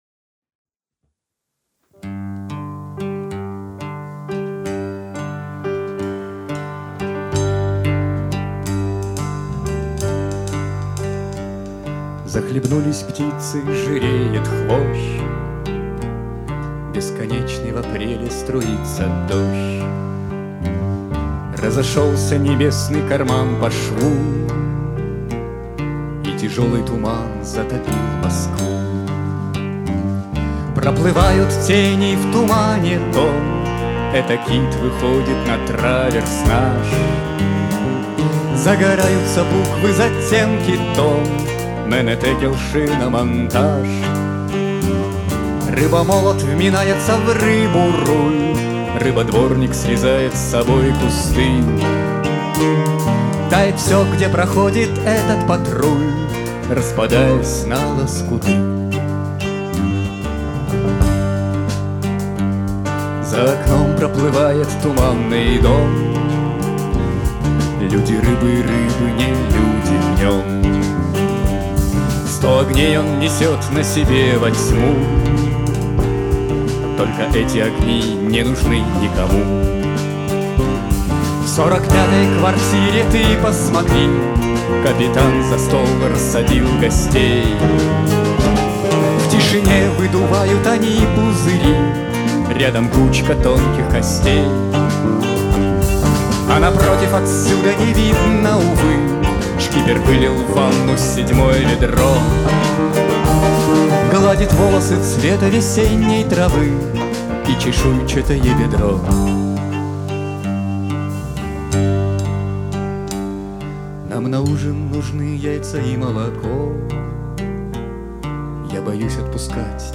Новогодний концерт 27.12.2019
ударные
контрабас, бас-гитара
виолончель, вокал
клавишные, вокал
вокал и гитара